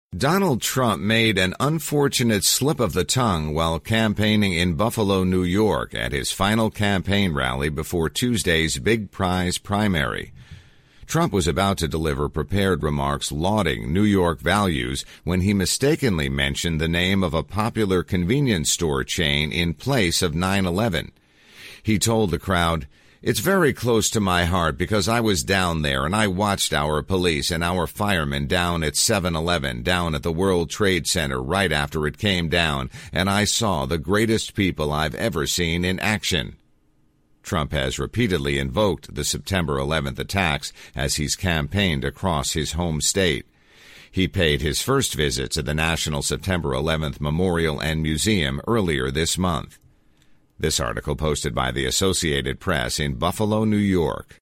(BUFFALO, N.Y.) — Donald Trump made an unfortunate slip-of-the-tongue while campaigning in Buffalo, New York, at his final campaign rally before Tuesday's big-prize primary.